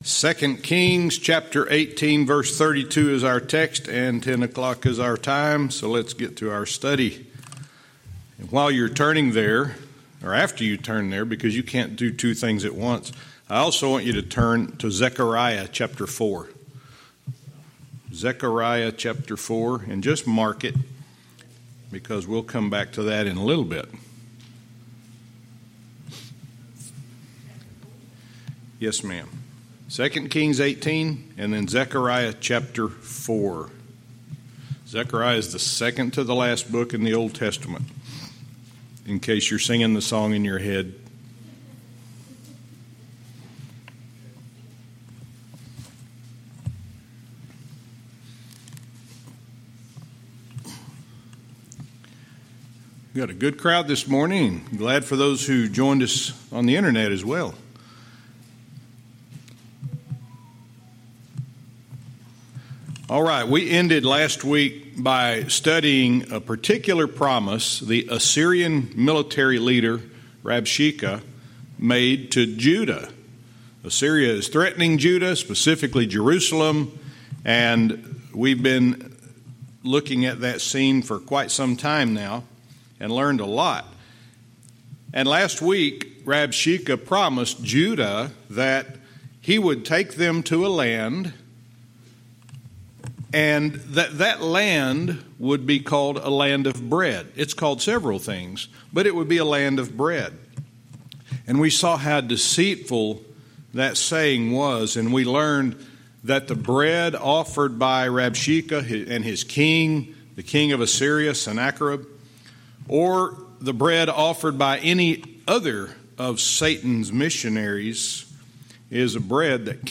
Verse by verse teaching - 2 Kings 18:32 Part 3